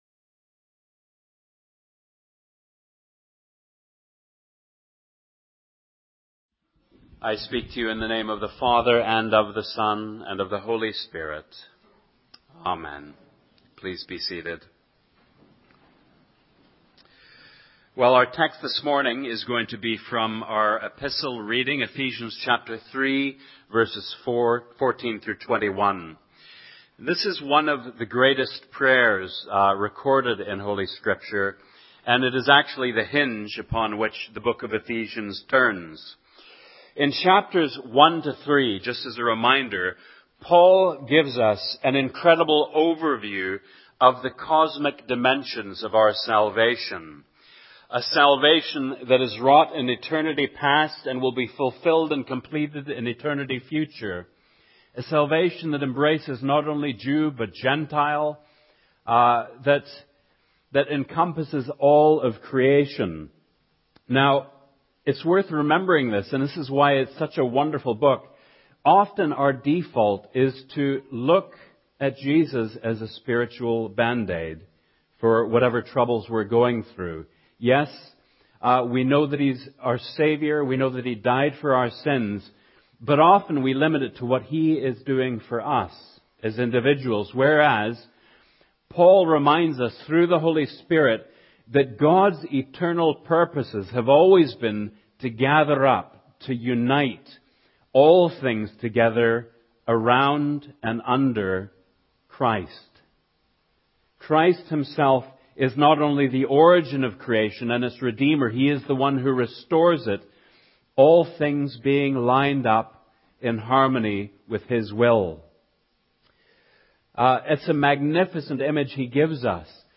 In this sermon, the speaker discusses the book of Ephesians, specifically focusing on chapters one to three and chapters four to six.